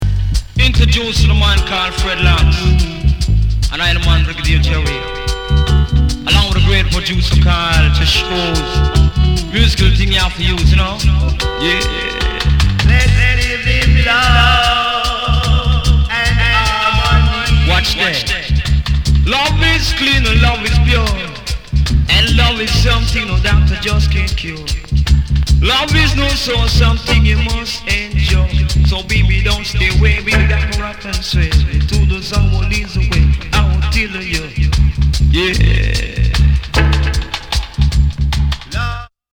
歌心あるスムース・ヴォイス・トースティング！！